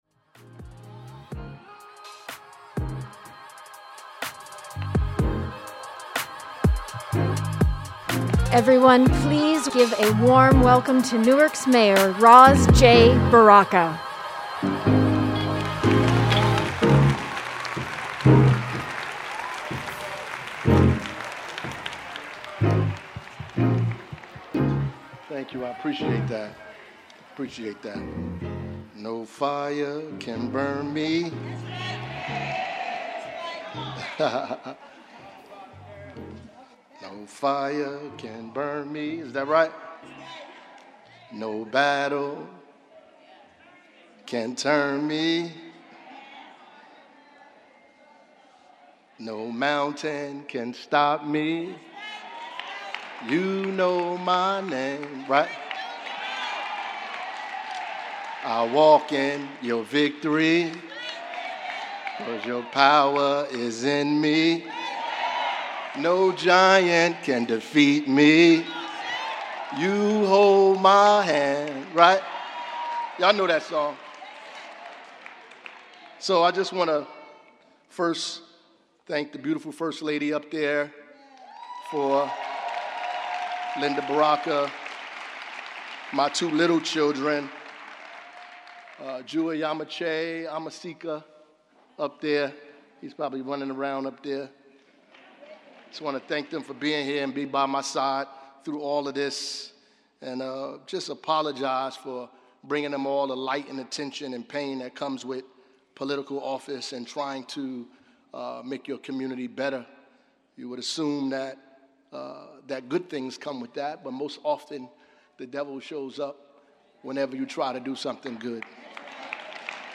Mayor Ras J. Baraka presented his 10th annual State of the City Address on March 12th, in a joyful, impassioned, and triumphant speech to the residents of Newark assembled at the New Jersey Performing Arts Center, located at 1 Center Street.